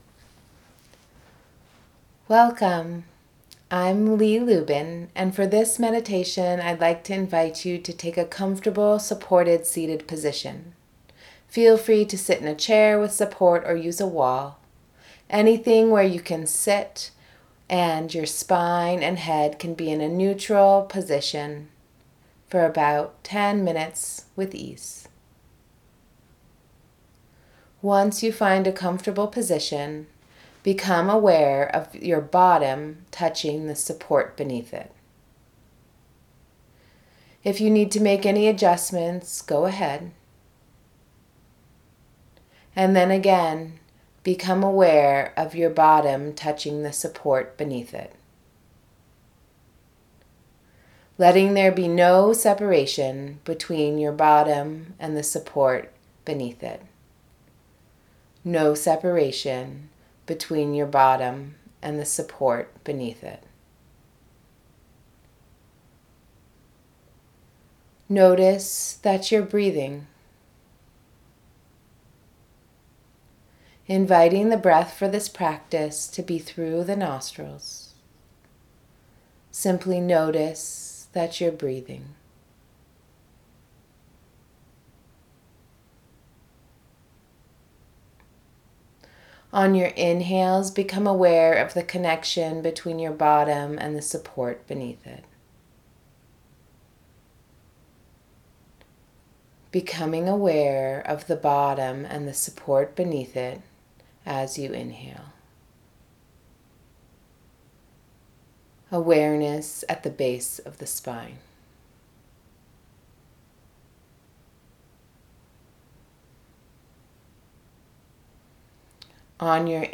Breath Visualization. Rooting and Growing
blue-tree-rooting-visualization.m4a